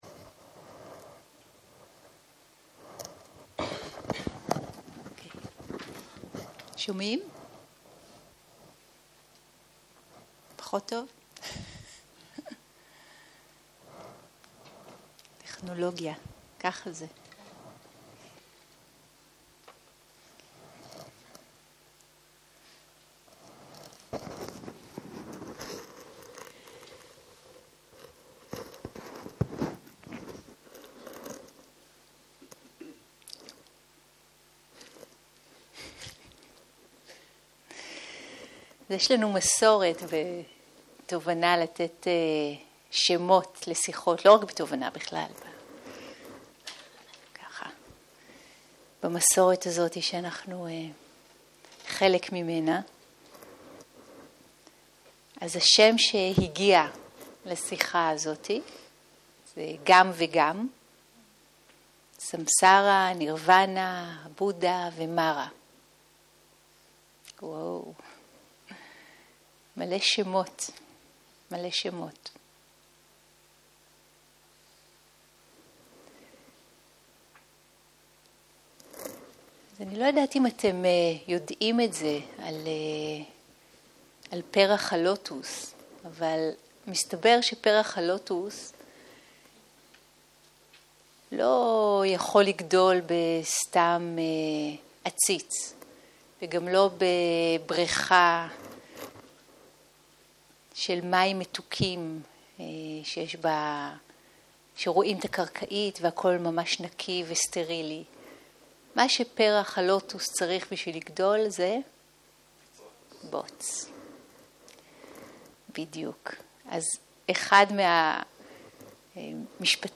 יום 2 - ערב - שיחת דהרמה - גם וגם, סמסרה, בודהה ומארה - הקלטה 4 0:00 0:00 סוג ההקלטה: Dharma type: Dharma Talks שפת ההקלטה: Dharma talk language: Hebrew